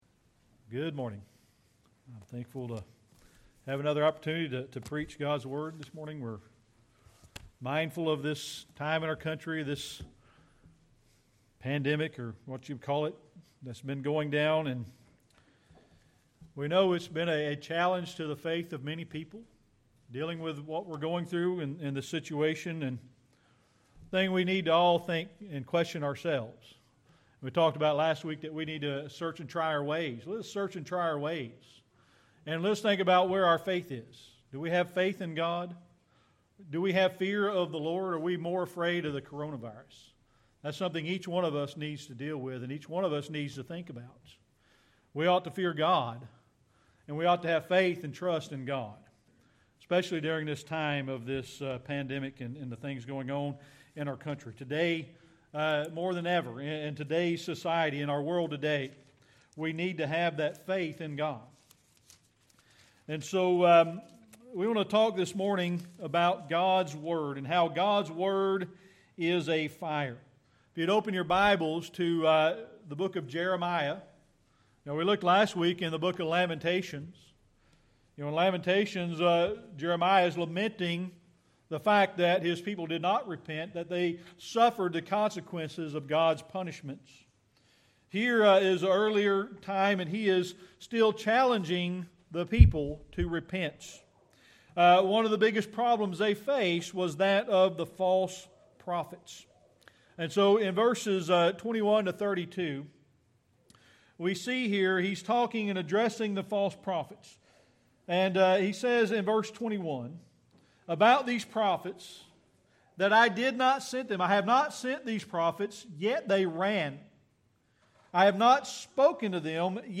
Jeremiah 23:29 Service Type: Sunday Morning Worship This morning we're going to talk about God's Word